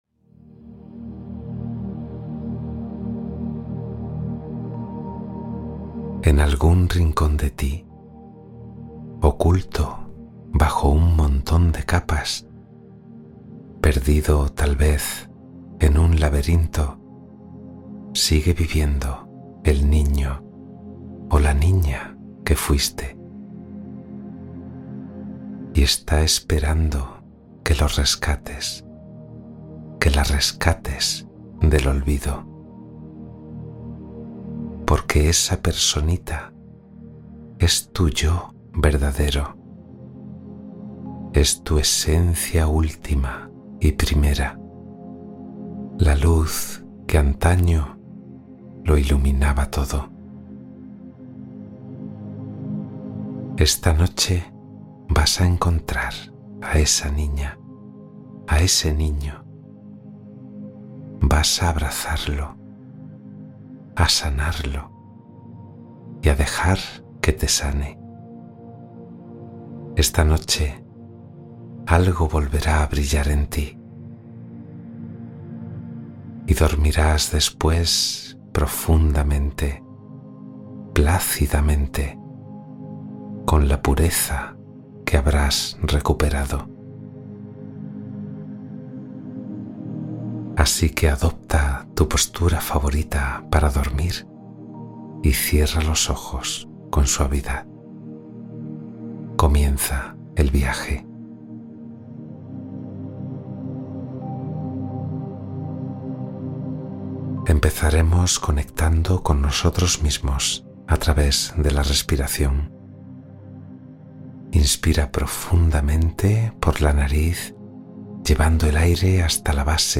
Meditación Nocturna de Sanación del Niño Interior